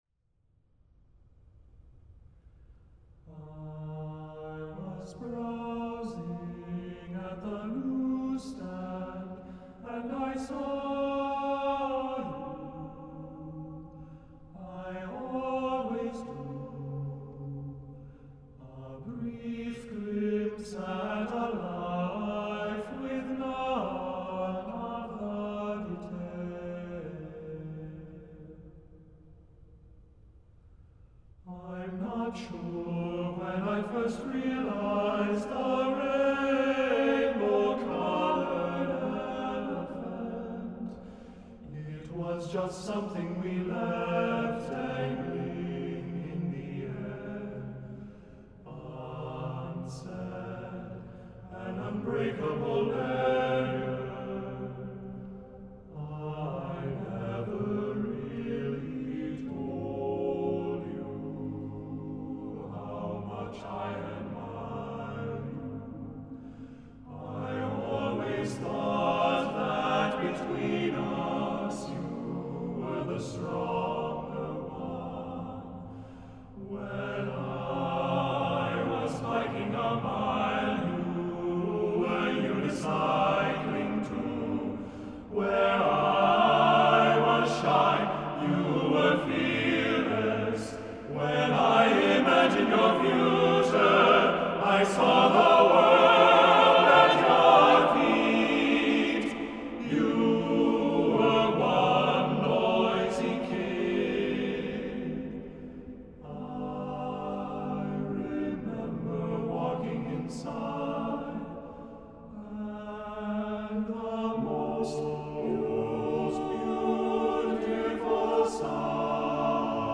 TTBB a cappella